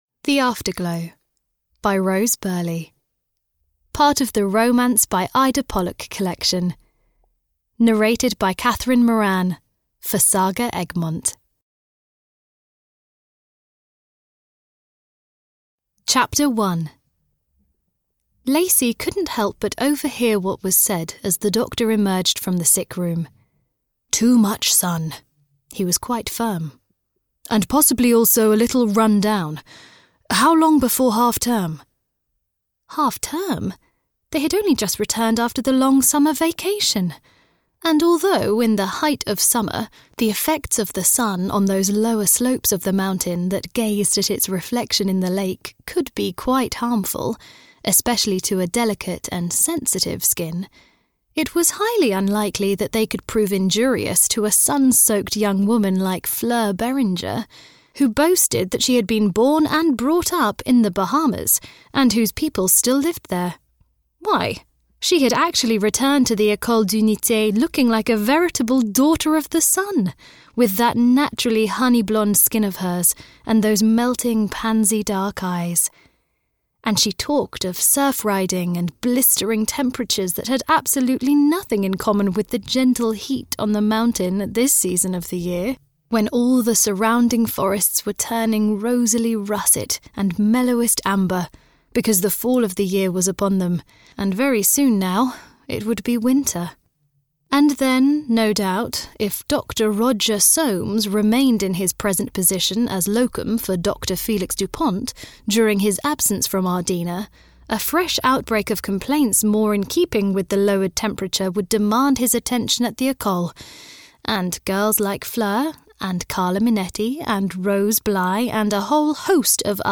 Audio knihaThe Afterglow (EN)
Ukázka z knihy